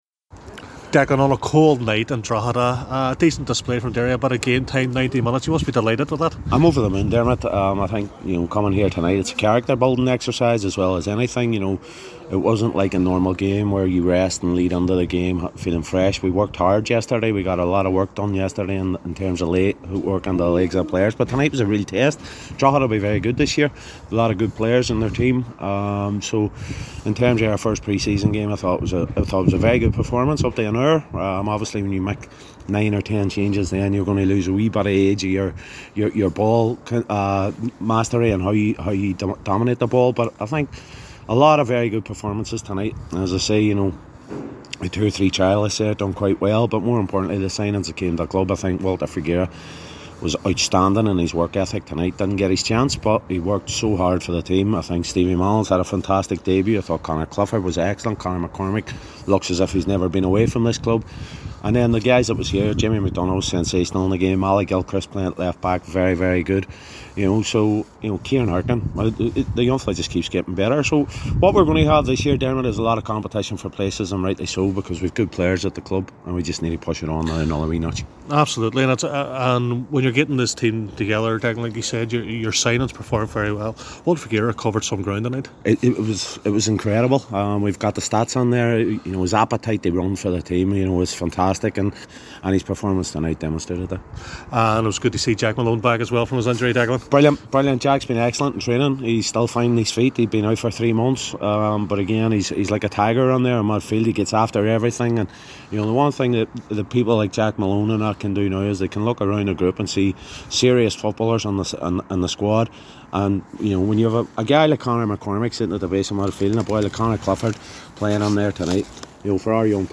caught up with the gaffer after the final whistle